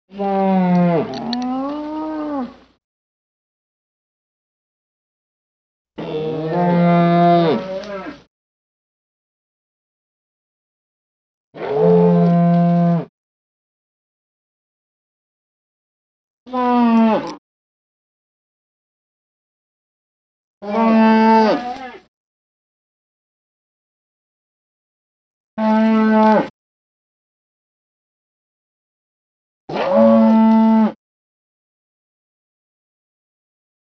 دانلود صدای گوزن شمالی از ساعد نیوز با لینک مستقیم و کیفیت بالا
جلوه های صوتی